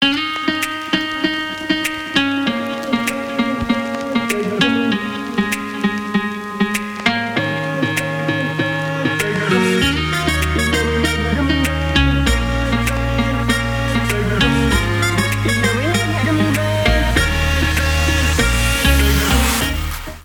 • Качество: 320, Stereo
гитара
заводные
спокойные
легкие
труба